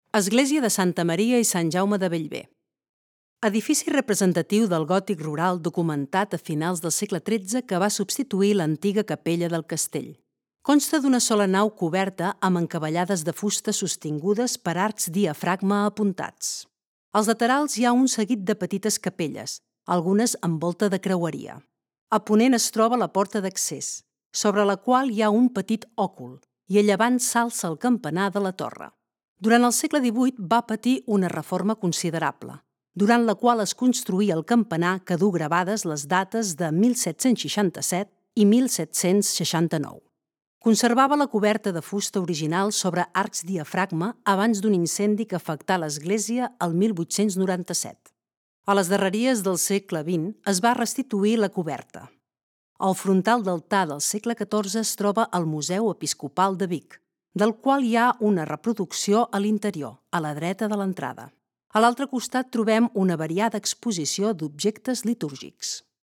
Església Sant Jaume de Bellver de Cerdanya Virtual tour Descriptive audio of the church Listen to the phrase to know what the church of Sant Jaume de Bellver is like.